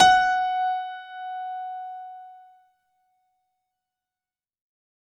F#4  DANCE-L.wav